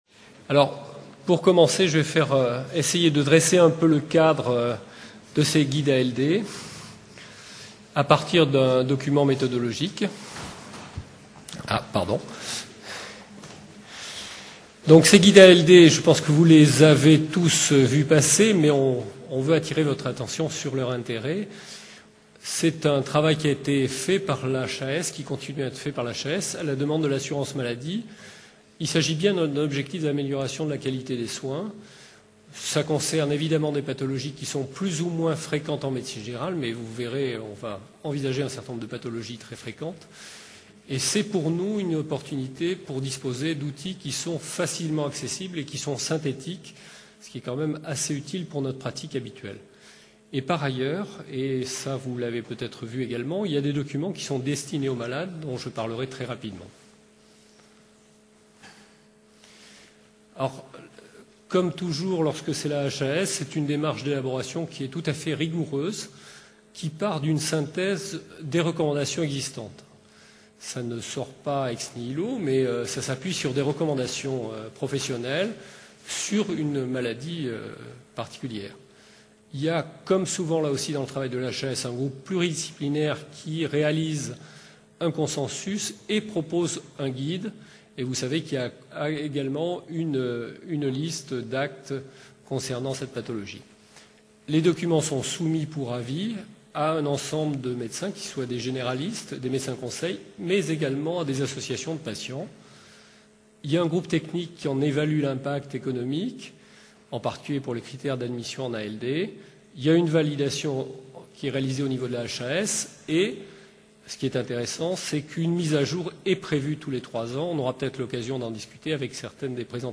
Journées Victor Segalen - Université Bordeaux 2 Formation Médicale Permanente : La Journée des Généralistes Organisée dans le cadre des Journées Victor Segalen 2010 par l’Unité Mixte de Formation Continue en Santé de l’Université Bordeaux 2, cette formation s’adresse avant tout aux médecins généralistes.